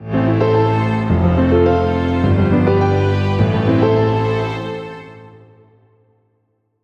Jingle Unterbrecher
Unterbrecher-Stereo.wav